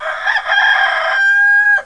ROOSTER.WAV